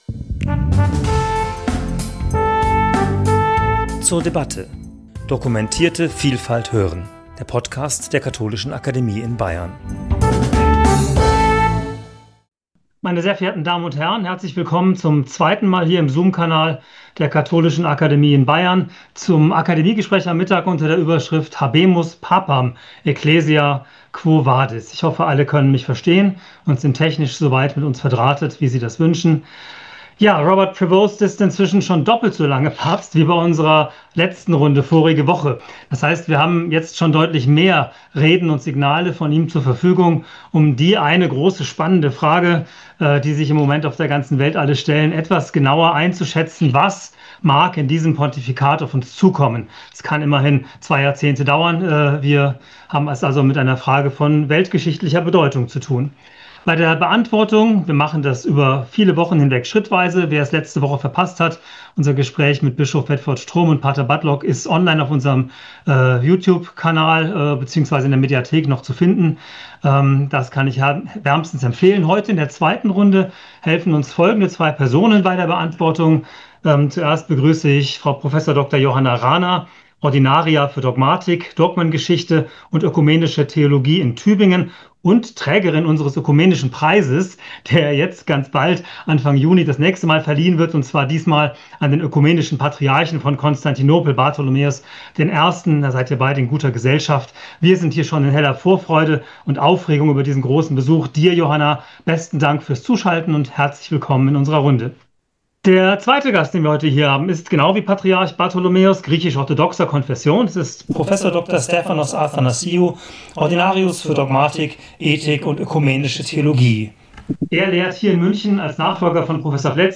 Gespräch zum Thema 'Habemus papam! Ecclesia, quo vadis?', Folge 2 ~ zur debatte Podcast